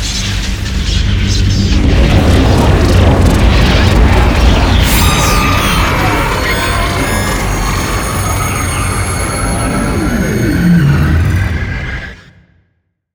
sshutdown.wav